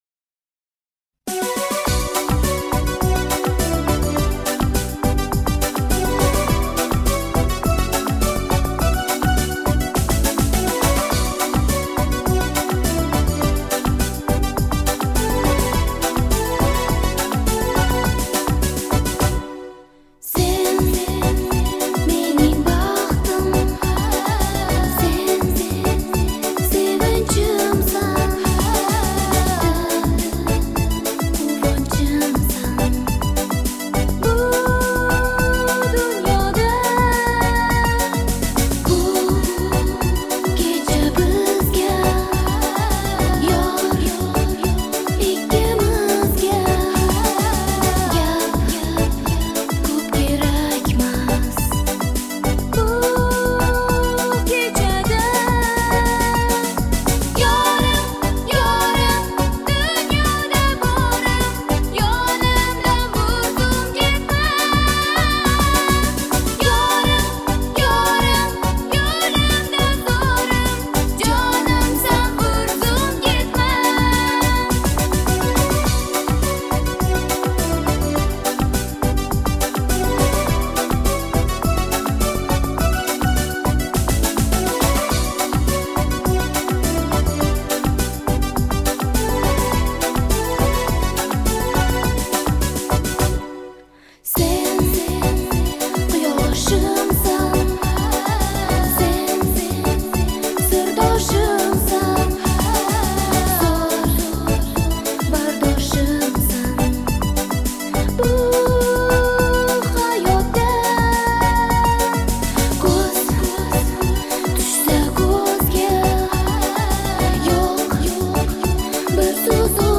• Жанр: Турецкие песни